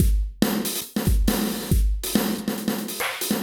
E Kit 29.wav